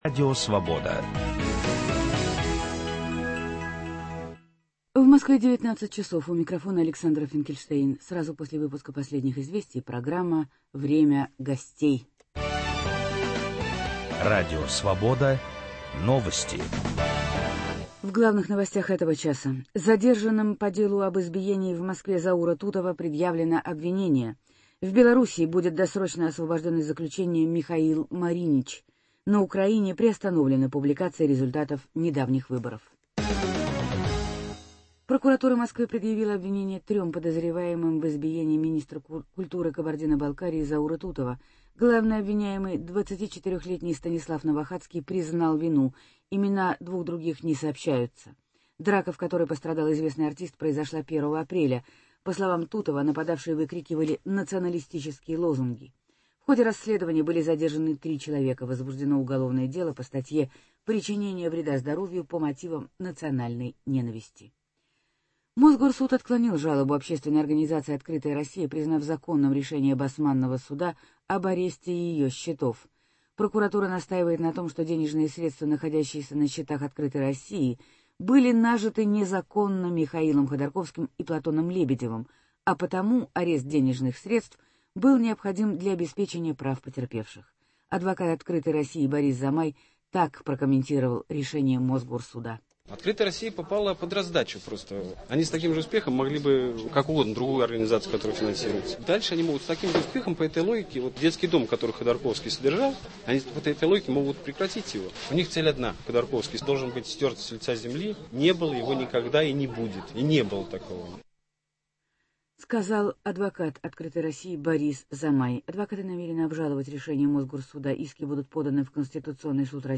Что ожидать российским садоводам от нового закона о "дачной амнистии"? Об этом и многом другом - в беседе